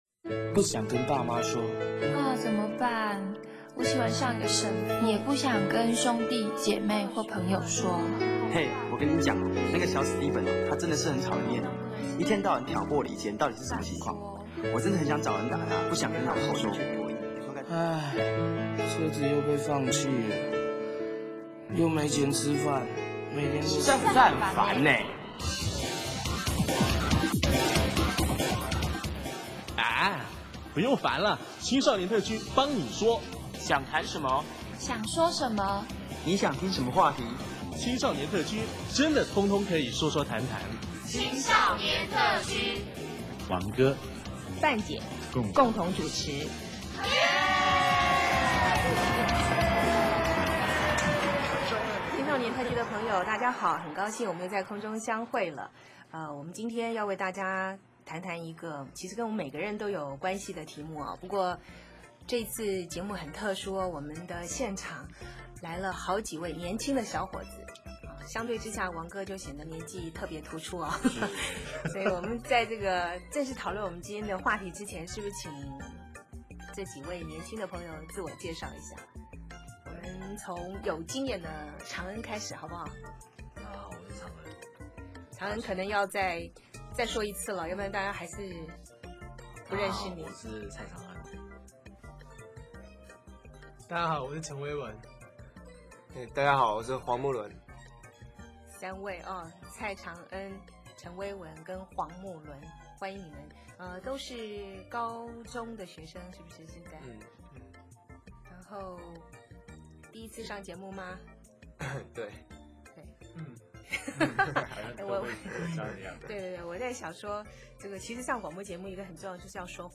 听听三位年轻男孩子的告白。